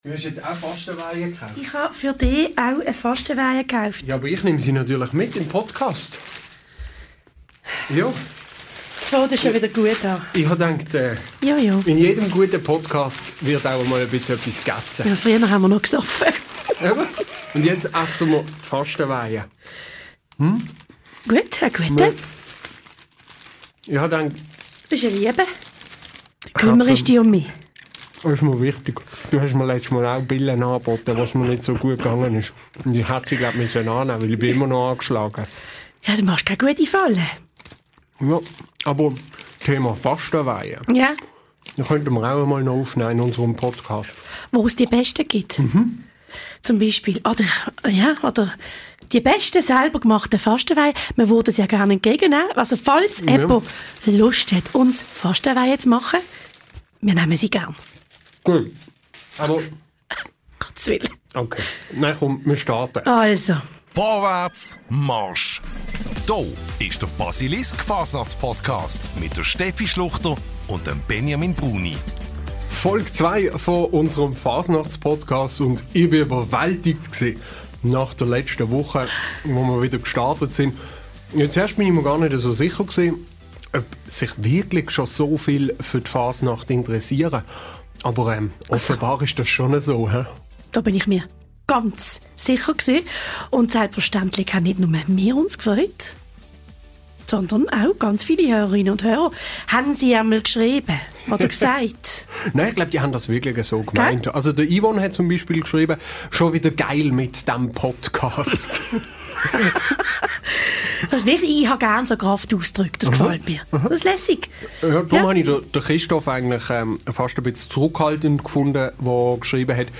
Des Weiteren sprechen die beiden über die Mimösli-Première im Kulturhuus Häbse und auch über das Fasnachtsbändeli im Theater Arlecchino.